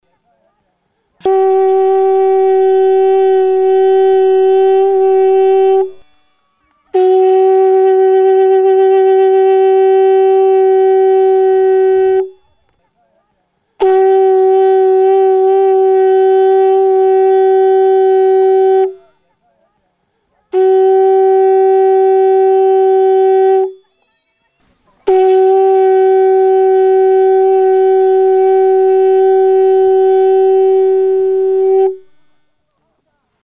Aerophones -> Lip vibration (trumpet) -> Natural (with / without holes)
Recorded with this music instrument.
Tronpeta gisako ahoa duen zezen adarra. Ez du tonu aldaketarako zulorik.